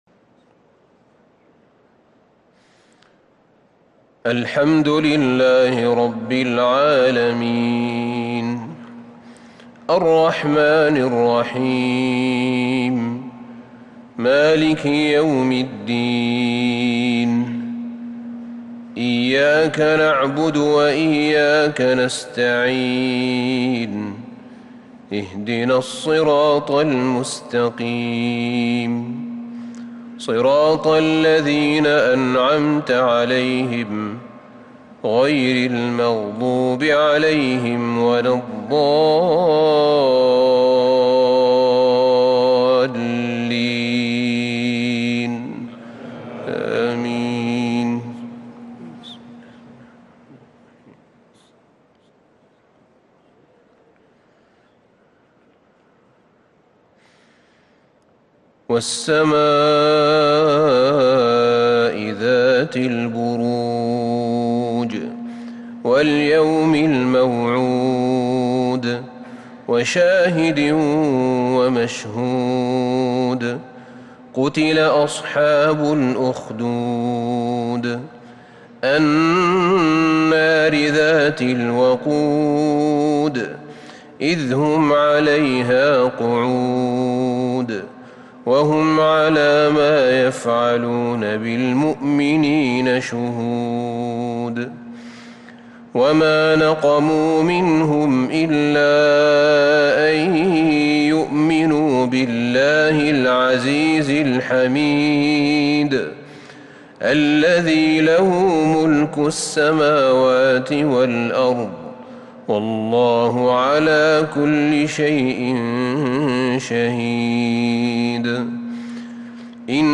عشاء الاثنين 1-1-1443هـ سورتي البروج و الطارق |Isha prayer from Surat Al-Buruj&At-Taariq 9-8-2021 > 1443 🕌 > الفروض - تلاوات الحرمين